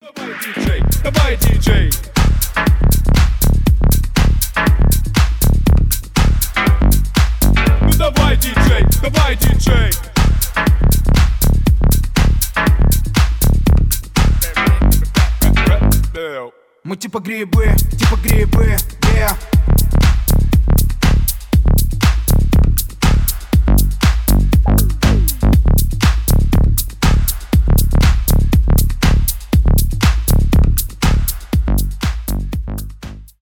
2016 » Русские » Рэп Скачать припев